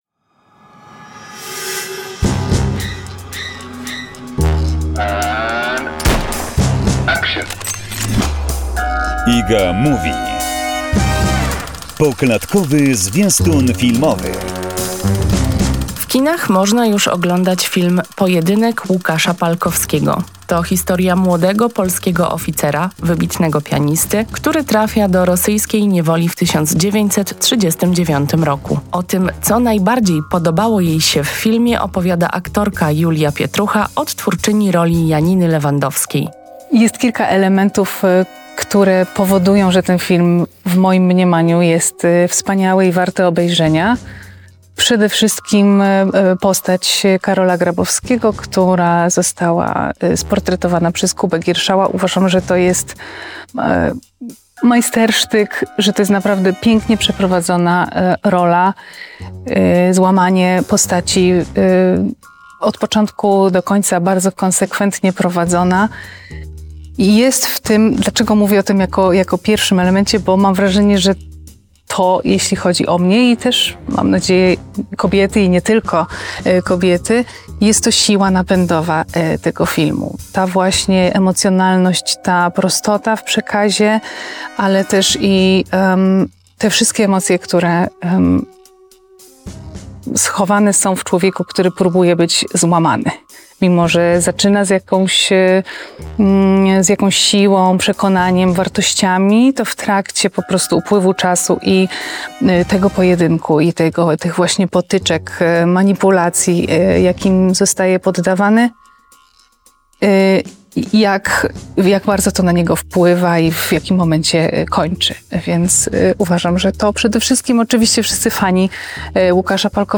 rozmowa z Julią Pietruchą cz. 2